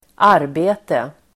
Uttal: [²'ar:be:te]